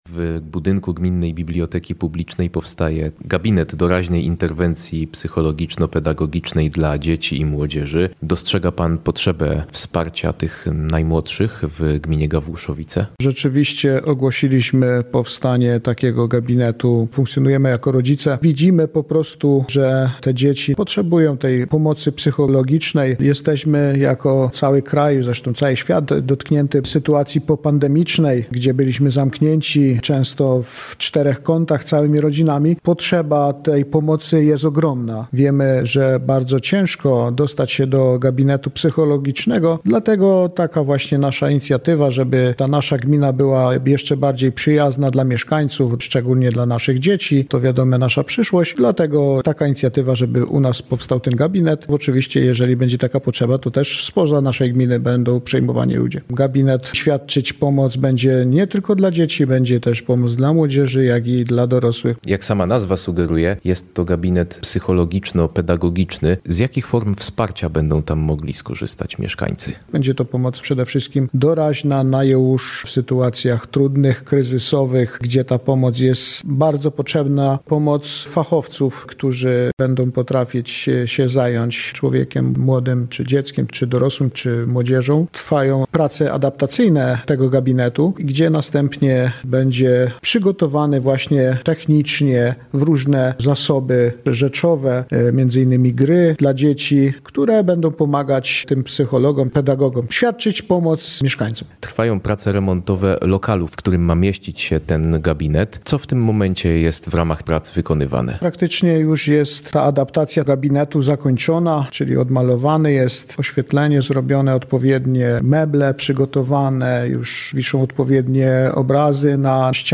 Będzie to pomoc doraźna, w sytuacji wymagającej natychmiastowego działania – podkreśla Bogusław Wojnarowski, wójt Gawłuszowic.
Relacja